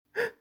Surprise Scary Sound Button: Meme Soundboard Unblocked
Sound Effects
Surprise Scary